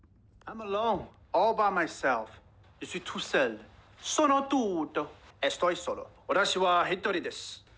第四个是多语种句子切换，7秒的音频里有英语、日语等5种语言，识别结果都进行了一一呈现。